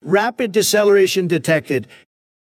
rapid-deceleration-detected.wav